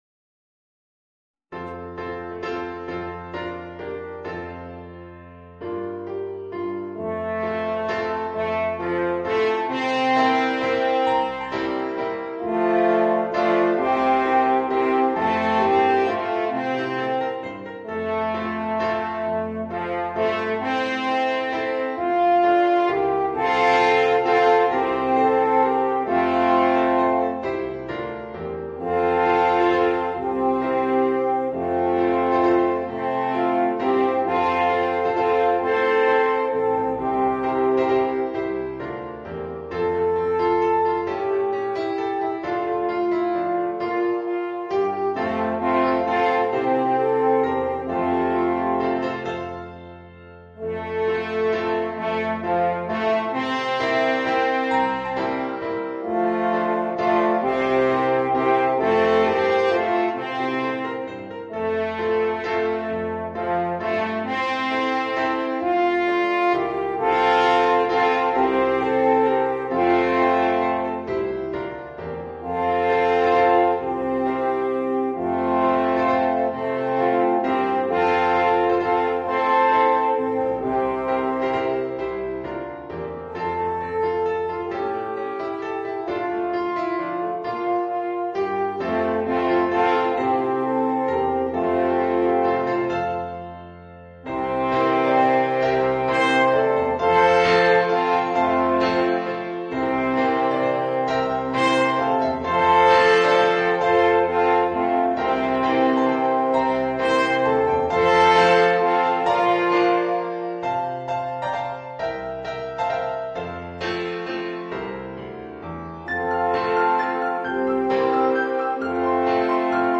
für 1, 2 oder 3 Alphörner in F und Klavier